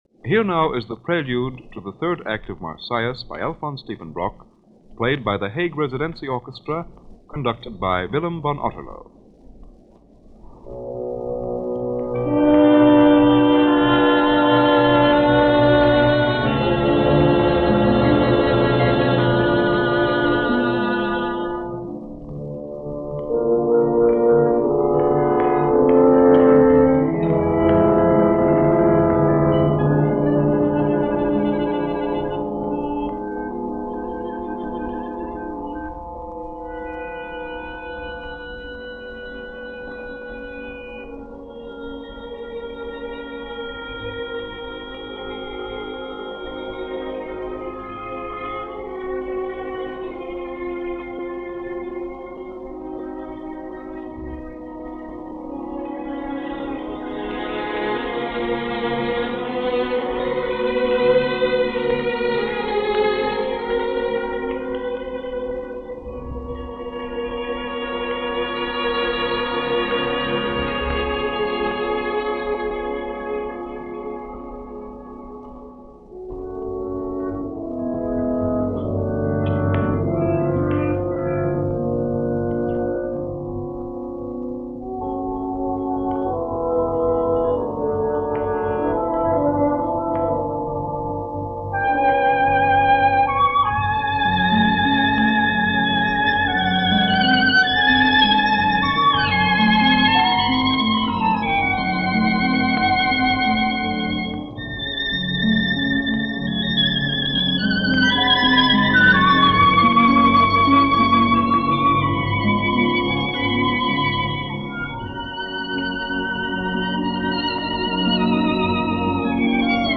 Hague Residentie Orkest – Willem van Otterloo, Cond
Something a bit different this week – from the Radio Nederland series Netherlands Composers, issued by their Transcription department circa 1950 – a performance of the Overture to Act 3 of Marsyas with the Hague Residentie Orchestra conducted by Willem van Otterloo.
This performance, a radio broadcast recording from circa 1950 (no dates on the transcription disc and no notes), and features the newly appointed Music Director of The Hague Residentie Orchestra, Willem von Otterloo.
Sadly, the 16″ discs this piece was recorded on have seen better days – trying to get rid of as much annoying noise without sacrificing the music can be tricky.